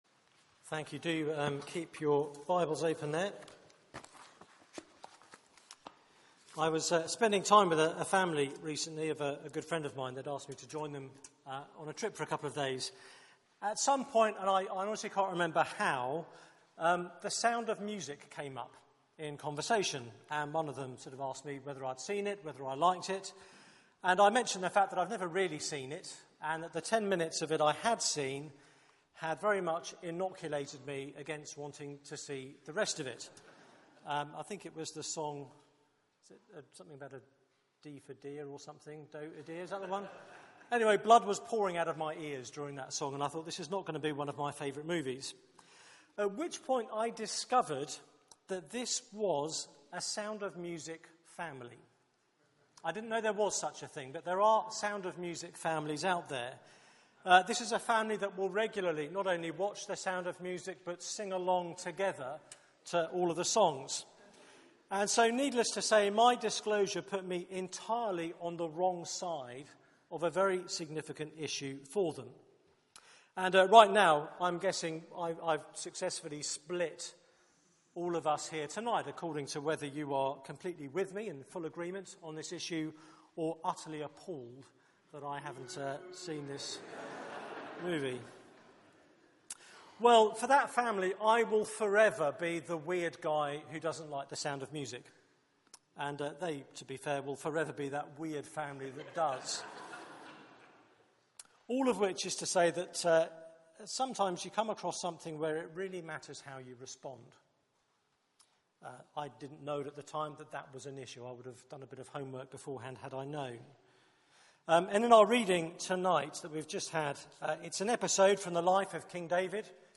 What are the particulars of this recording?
Media for 6:30pm Service on Sun 13th Jul 2014